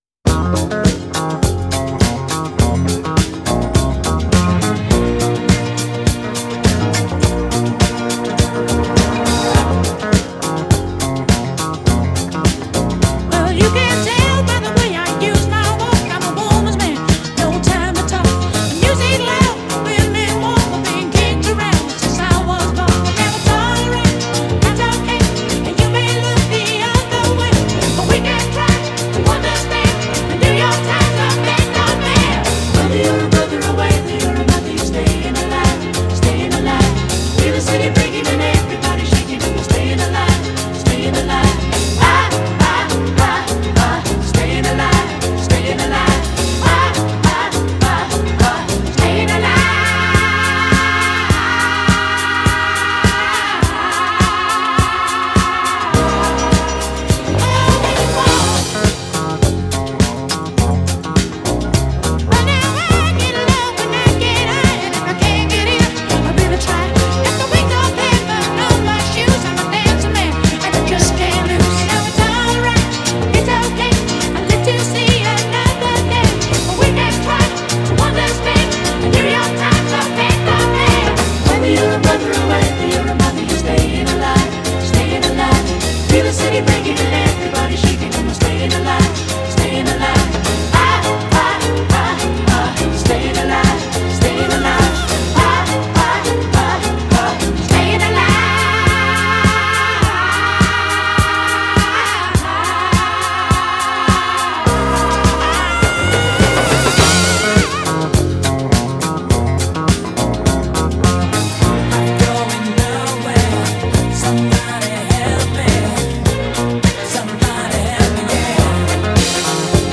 Genre: Funk / Soul, Stage & Screen
Style: Soundtrack, Disco
Bass, Vocals
Drums
Guitar, Vocals
Keyboards
Percussion